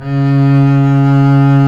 Index of /90_sSampleCDs/Roland - String Master Series/STR_Cb Bowed/STR_Cb2 f vb